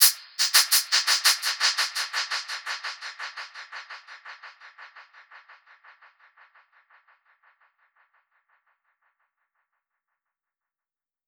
Index of /musicradar/dub-percussion-samples/85bpm
DPFX_PercHit_B_85-11.wav